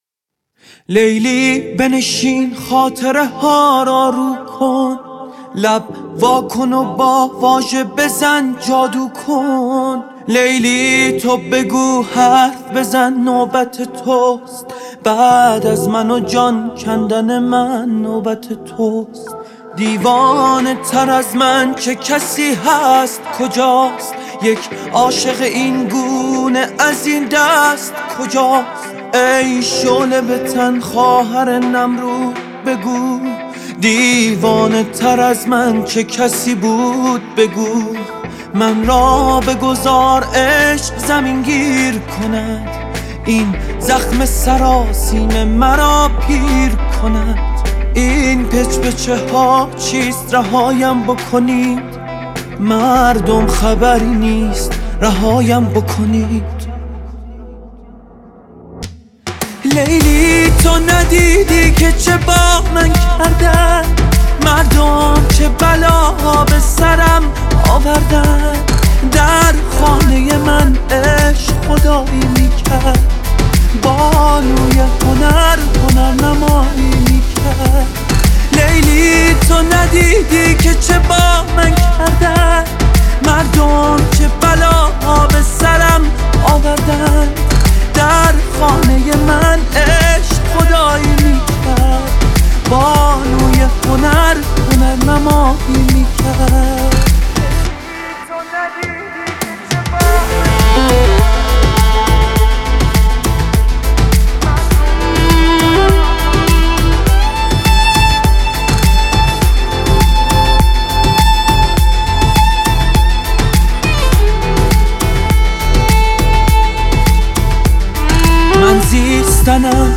در سبک پاپ